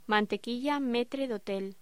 Locución: Mantequilla metre de hotel